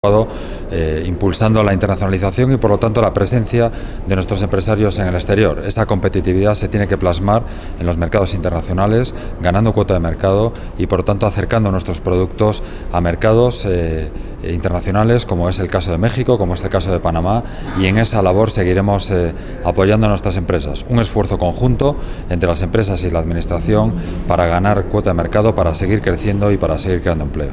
Audio (4) coas declaracións do presidente da Xunta facenda balance da súa estadía en México 822.86 KB